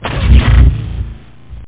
Amiga 8-bit Sampled Voice
1 channel
Rocket.mp3